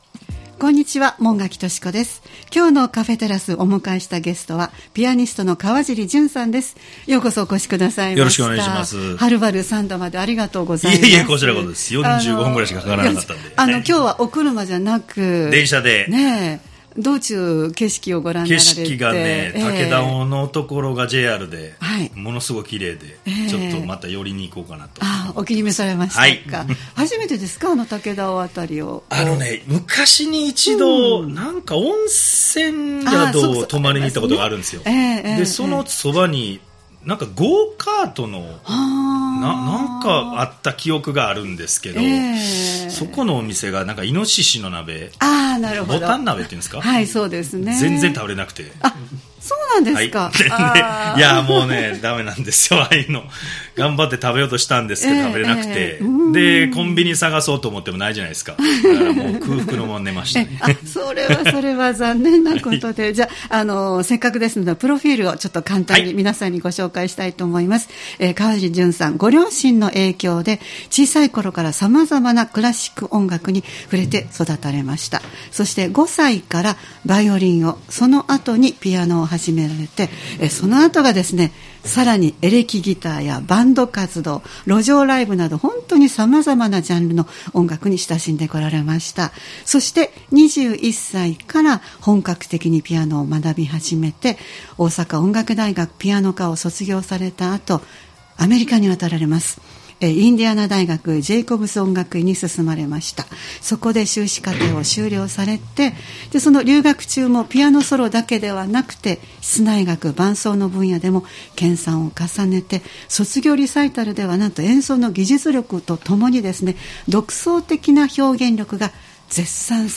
様々な方をスタジオにお迎えするトーク番組「カフェテラス」（再生ボタン▶を押すと放送が始まります）